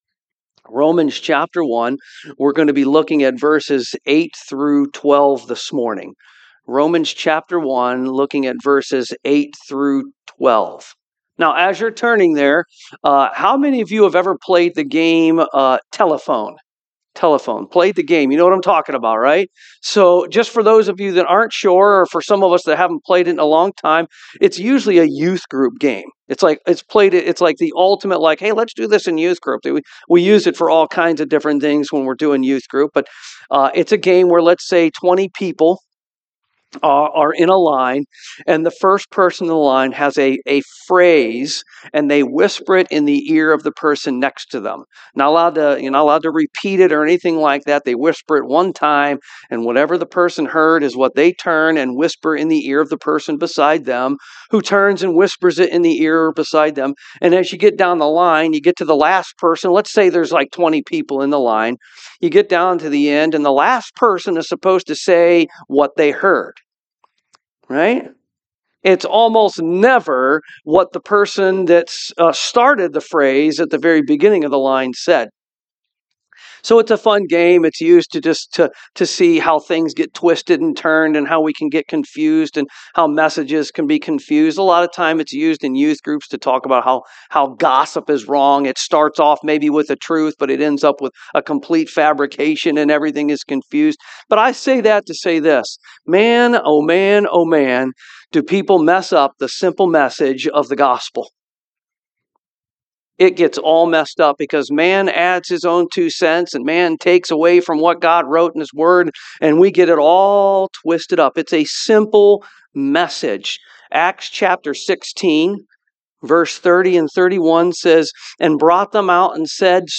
Service Type: AM